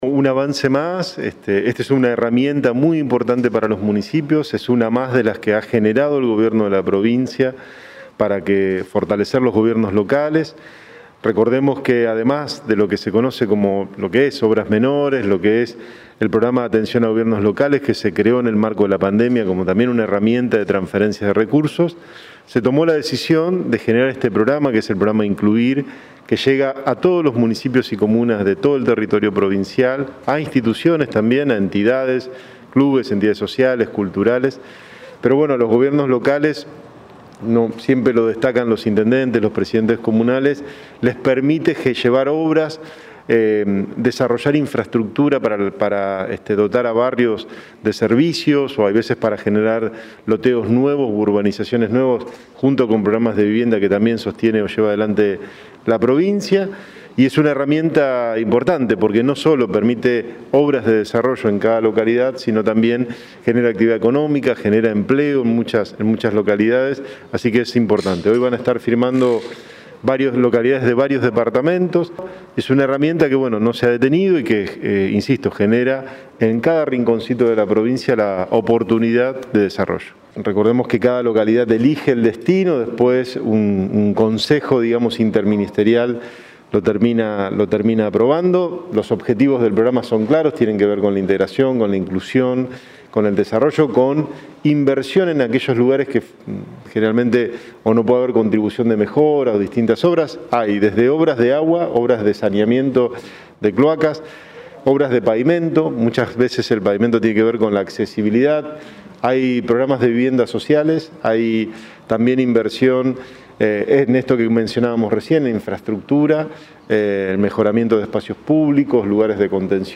Declaraciones de Freyre y de Rigo